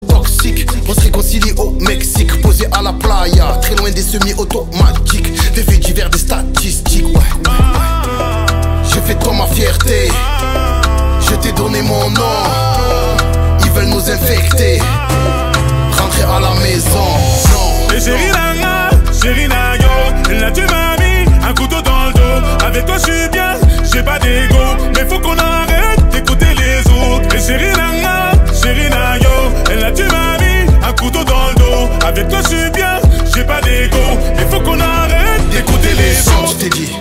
Catégorie Rap / Hip Hop